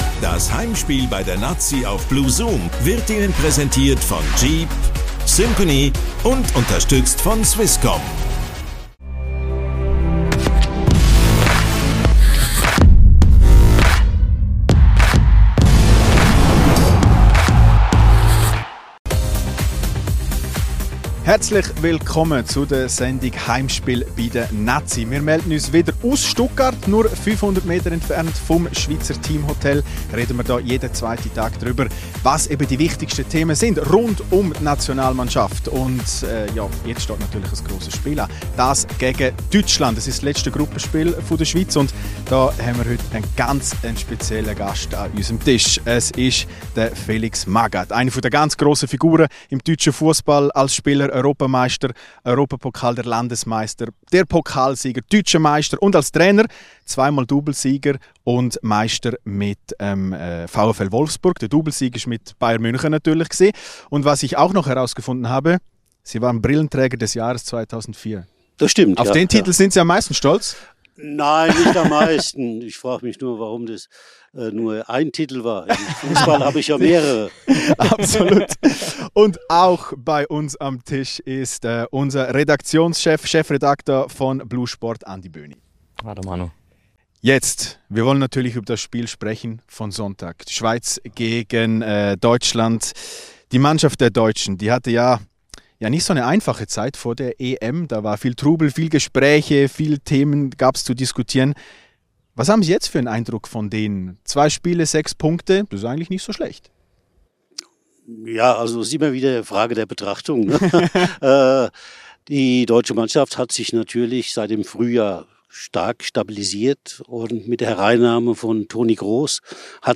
Heimspiel bei der Nati // Spezial-Sendung mit Felix Magath ~ Heimspiel ⎥ Der Fussball-Talk Podcast